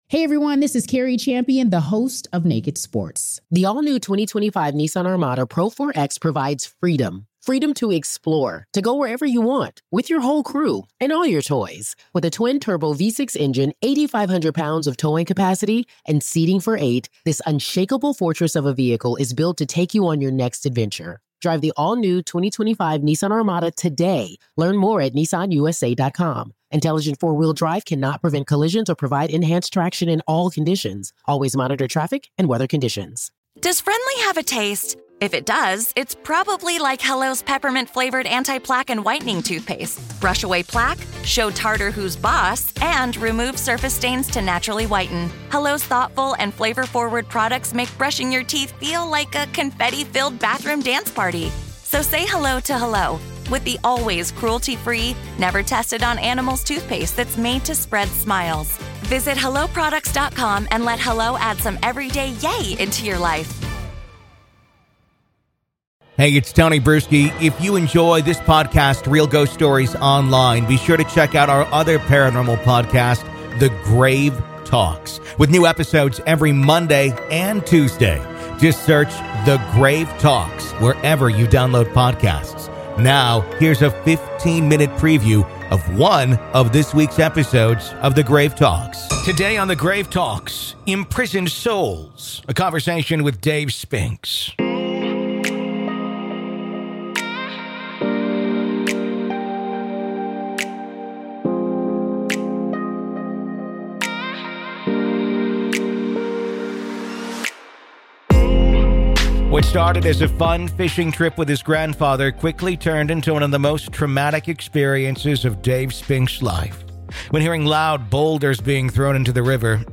Real Ghost Stories Online